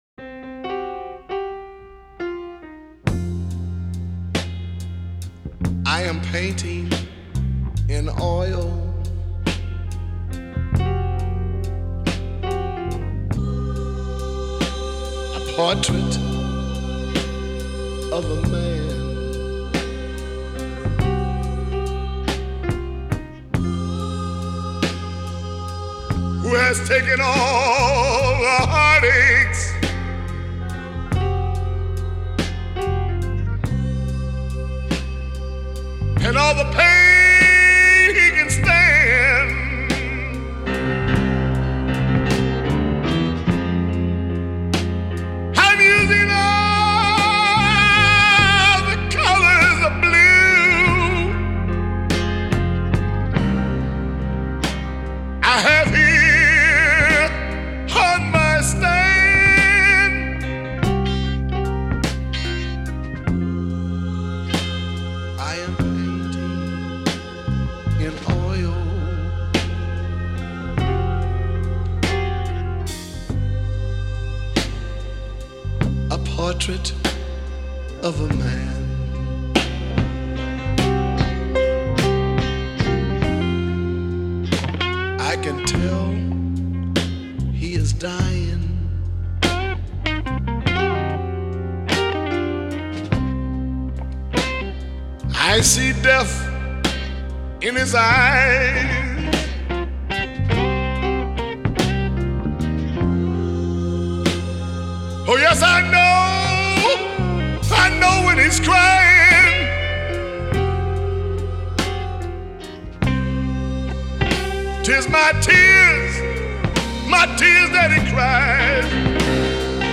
вот тебя пробило на блюзы)) в тему конечно..!